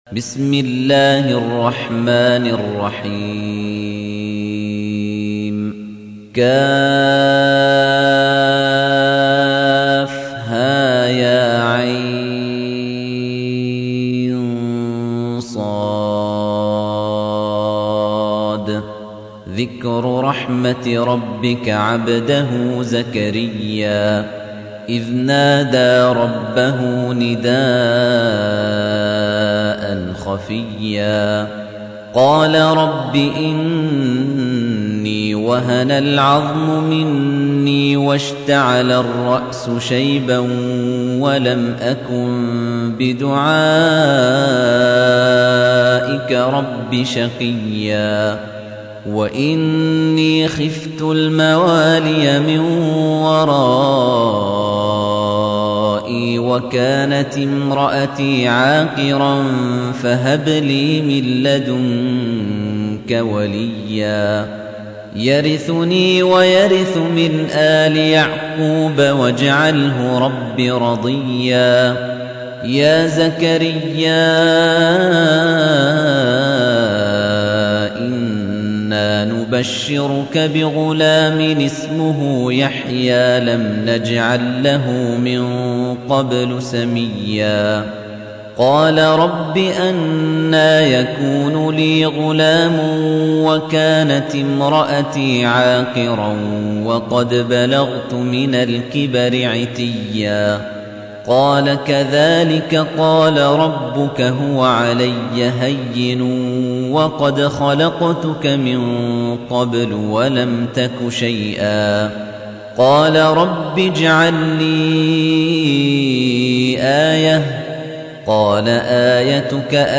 تحميل : 19. سورة مريم / القارئ خليفة الطنيجي / القرآن الكريم / موقع يا حسين